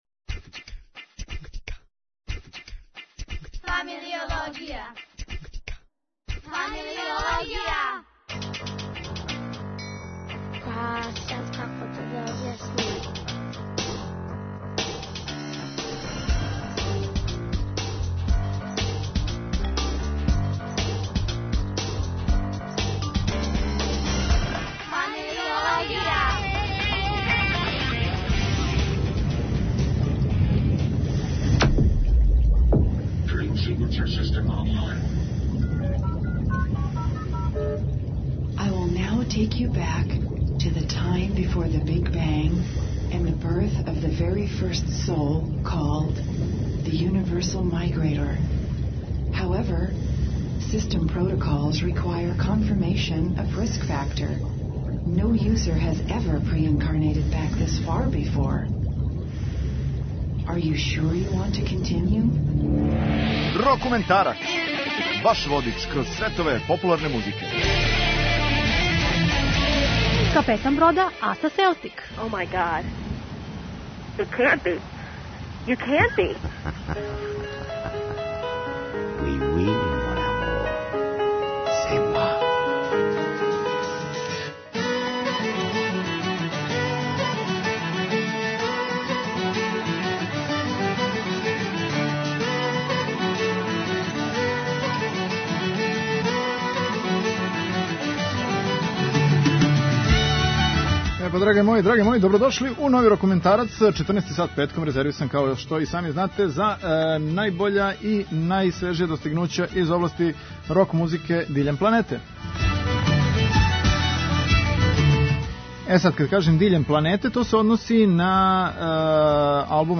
придружује и ведета прогресивног рока 90-их